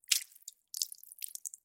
Звуки операции
Хирург накладывает швы в операционной во время вмешательства